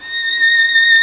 Index of /90_sSampleCDs/Roland - String Master Series/STR_Violin 1-3vb/STR_Vln3 % + dyn
STR VLN3 A#5.wav